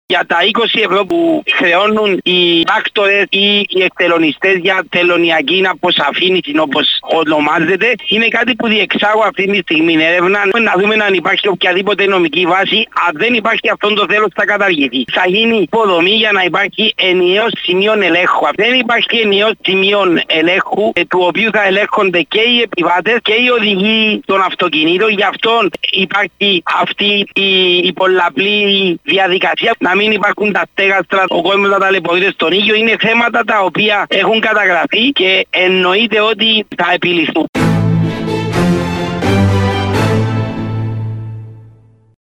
Υφυπουργός Ναυτιλείας:
ΥΦΥΠΟΥΡΓΟΣ-ΝΑΥΤΙΛΕΙΑΣ.mp3